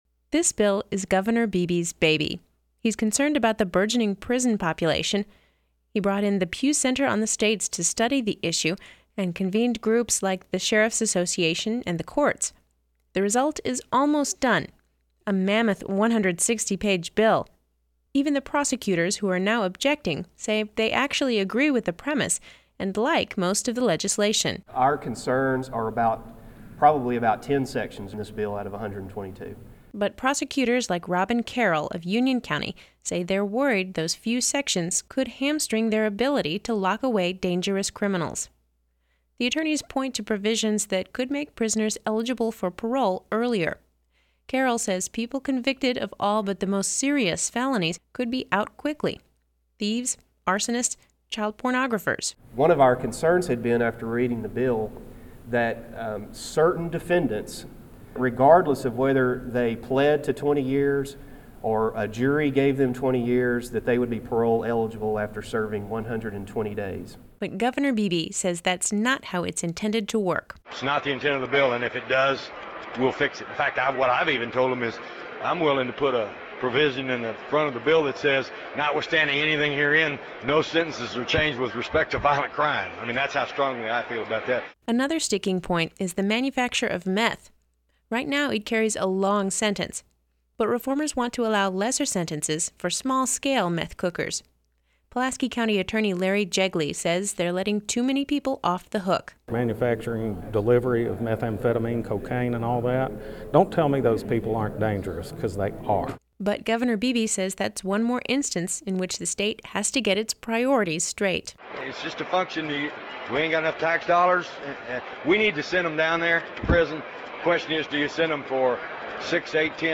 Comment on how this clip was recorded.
Though prison reform legislation hasn’t been filed yet, plenty of conversation about the bill has been taking place. We have this report from Little Rock.